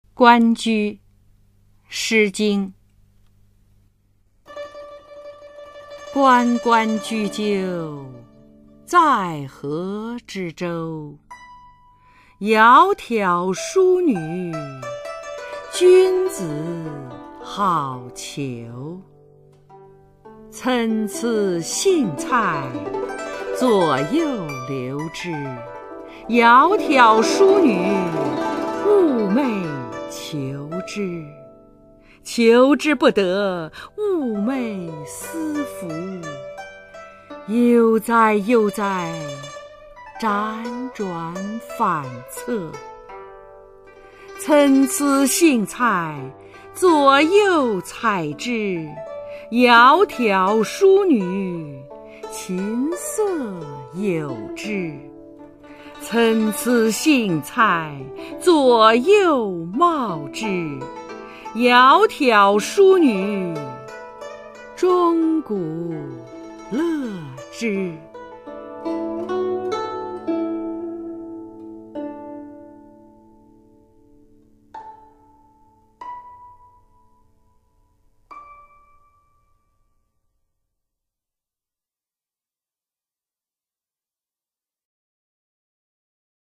[先秦诗词诵读]诗经-关雎 朗诵